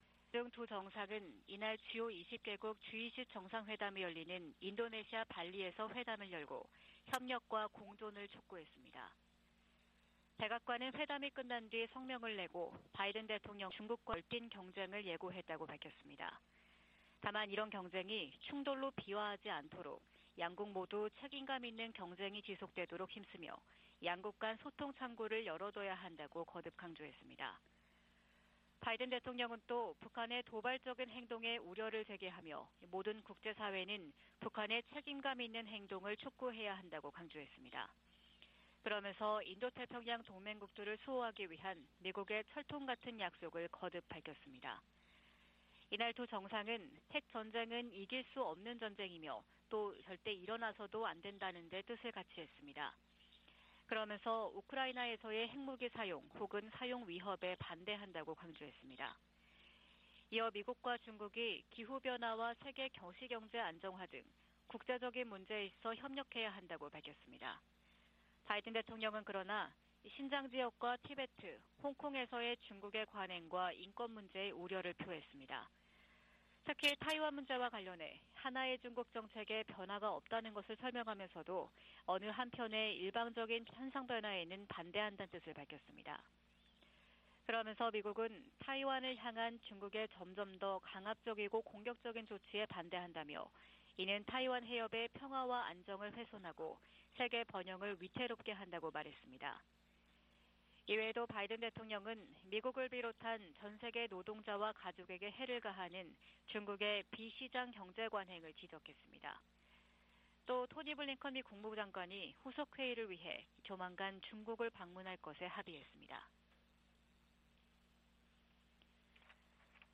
VOA 한국어 '출발 뉴스 쇼', 2022년 11월 15일 방송입니다. 미국과 한국, 일본 정상들이 미한일 정상이 13일 캄보디아에서 만나 북한 문제와 관련해 억제력을 강화하기 위해 협력하기로 합의했습니다. 미,한,일 정상의 ‘프놈펜 공동성명’은 북한의 고조된 핵 위협에 대응한 세 나라의 강력한 공조 의지를 확인했다는 평가가 나오고 있습니다.